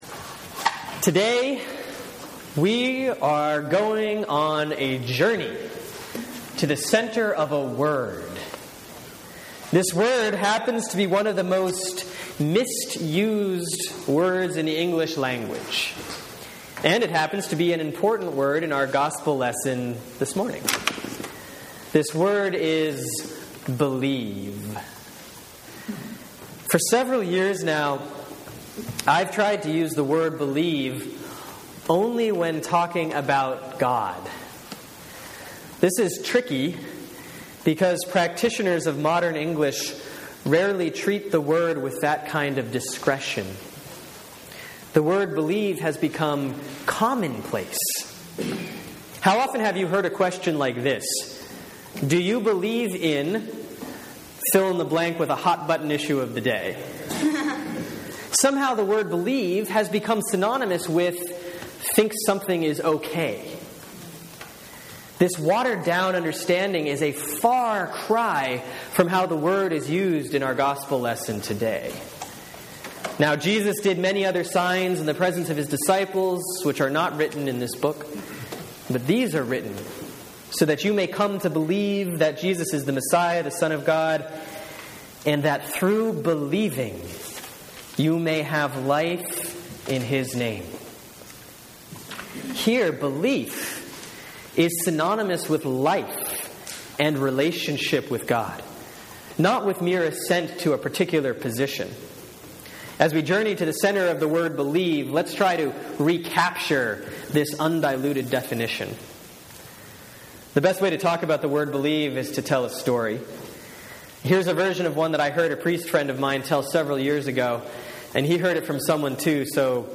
Sermon for Sunday, April 27, 2014 || Easter 2A || John 20:19-31